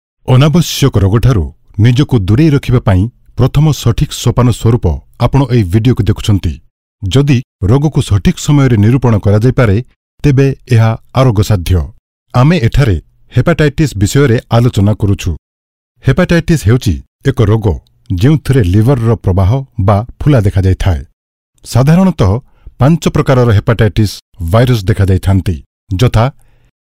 Oriya Voice Over Sample
Oriya Voice Over Male Artist 3